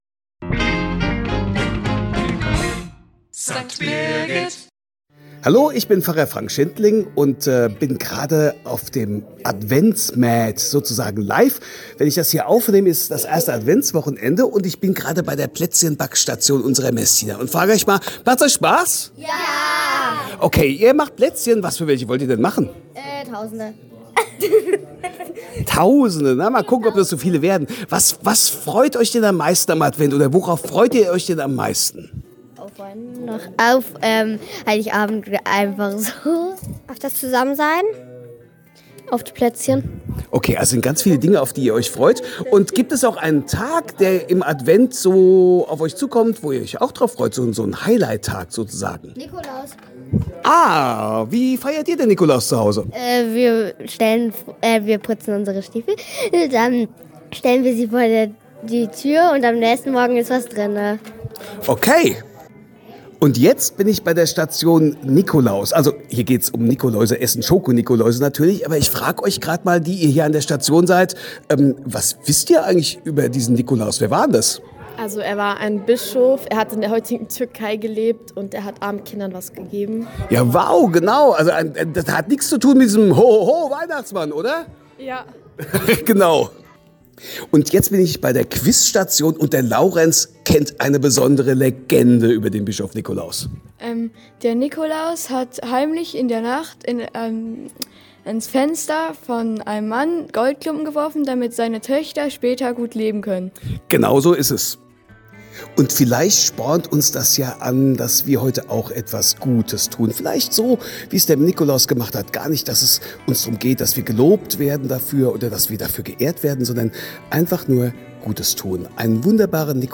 mit Messdienern auf dem Weihnachts-MAD